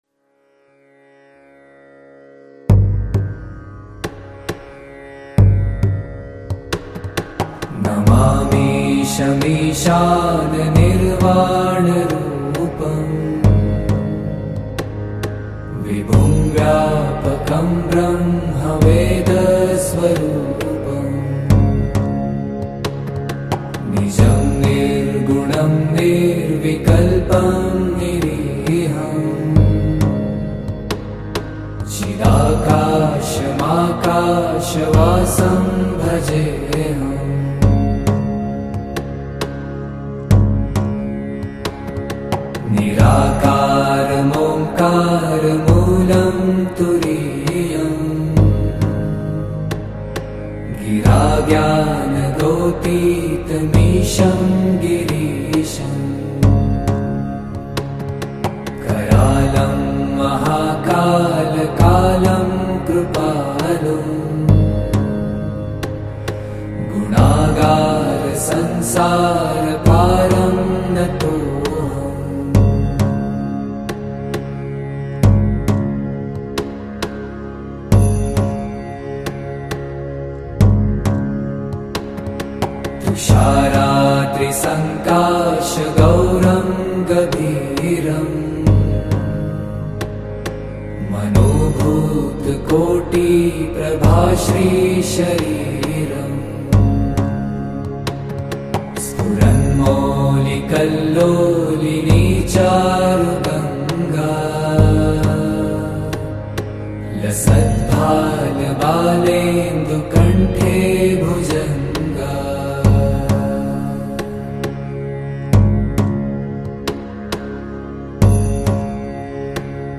Releted Files Of Devotional